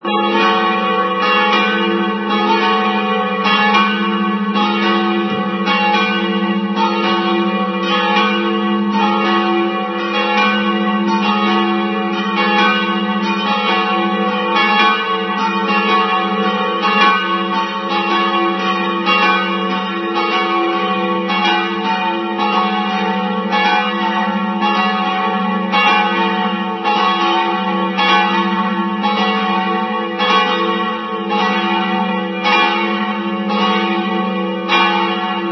O samotě při kostele stojí hranolová, věžovitá, velmi náročně barokně členěná zvonice z roku 1670 zakončená cibulovou střechou s lucernou známou z mnoha Alšových kreseb.
Zvony jsou z 16. a 19. století.
ZVONY - (MP3, 105 kB) - BELLS
mirzvony.mp3